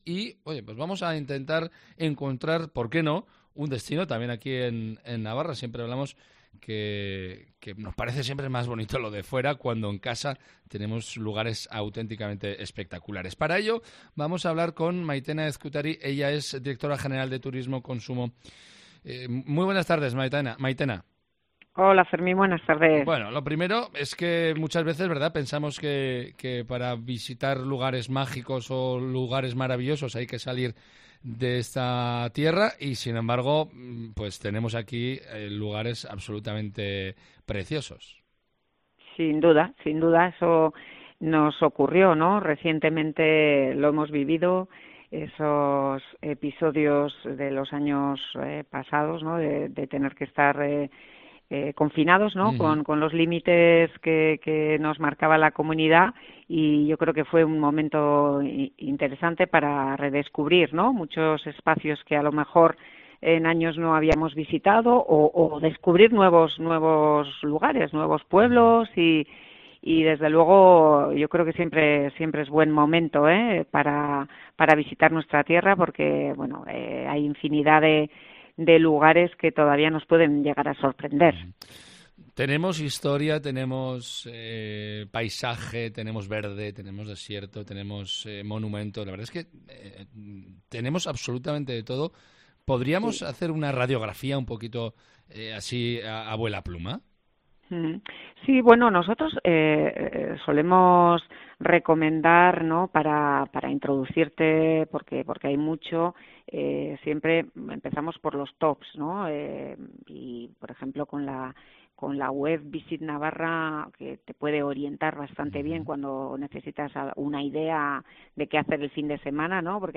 Entrevista a Maitena Ezkutari, directora general de Turismo del Gobierno de Navarra